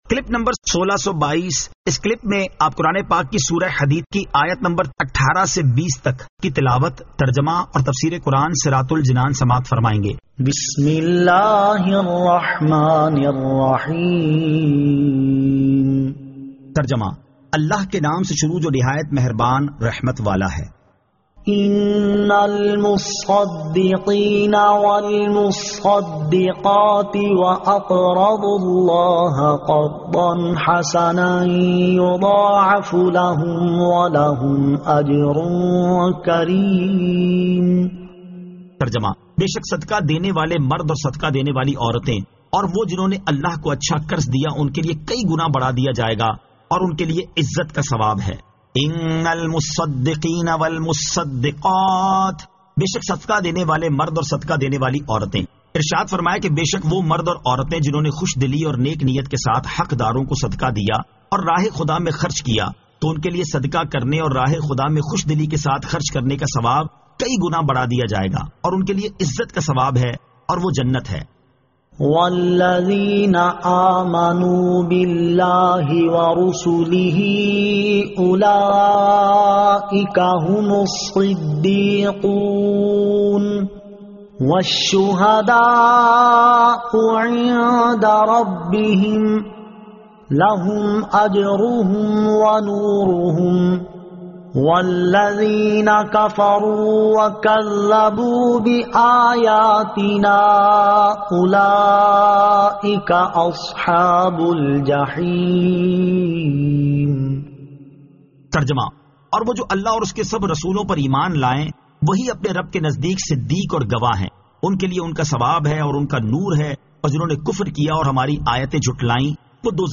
Surah Al-Hadid 18 To 20 Tilawat , Tarjama , Tafseer